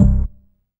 ORGAN-13.wav